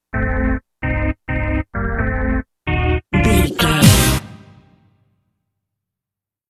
Aeolian/Minor
synthesiser
drum machine
90s